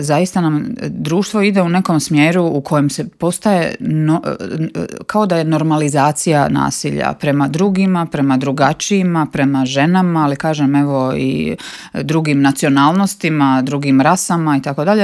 ZAGREB - U Intervjuu Media servisa ugostili smo saborsku zastupnicu i splitsku gradsku vijećnicu Centra Marijanu Puljak.